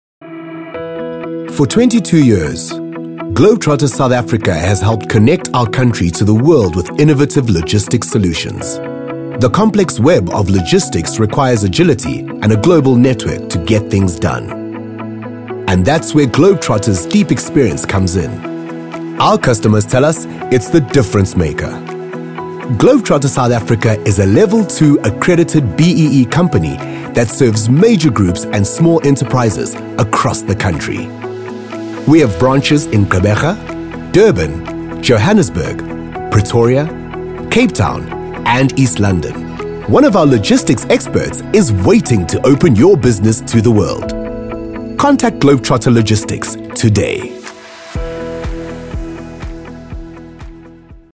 South Africa
captivating, charismatic, charming, magnetic